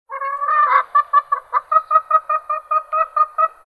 Farm Chicken Clucking Sound Effect
Animal Sounds / Chicken Sounds / Sound Effects
Farm-chicken-clucking-sound-effect.mp3